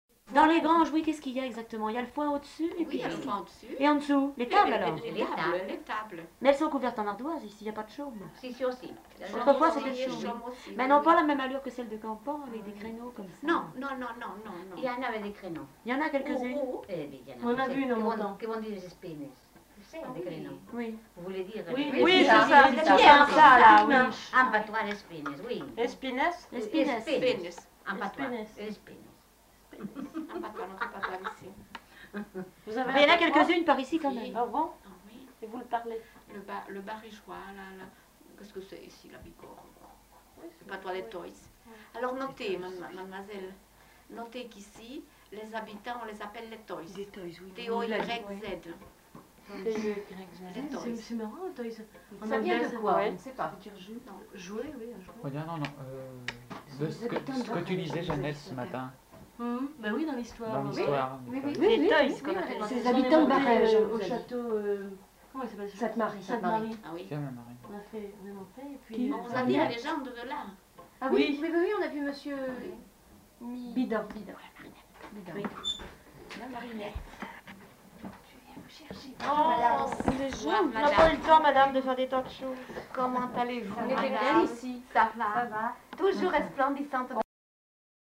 Aire culturelle : Bigorre
Genre : témoignage thématique
Notes consultables : Les deux informatrices ne sont pas identifiées.